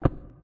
ui_interface_16.wav